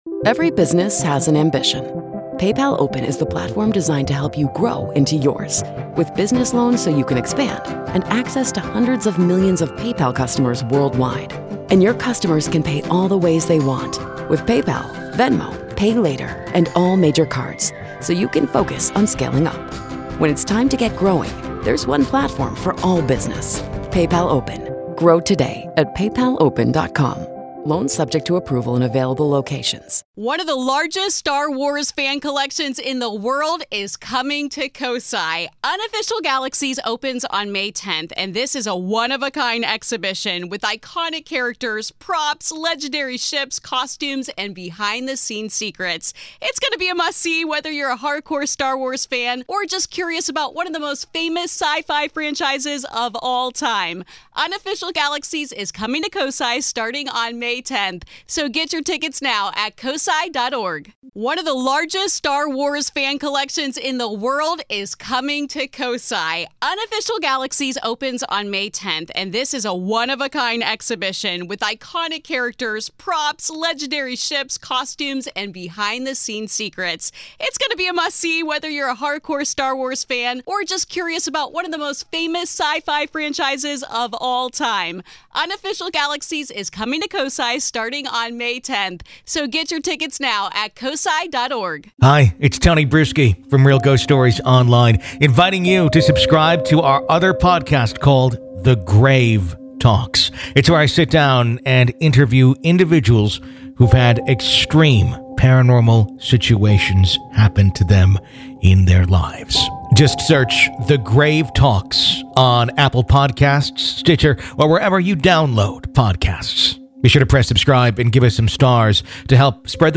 To listen to part two of our interview, you need to be a Grave Keeper (supporter of the show).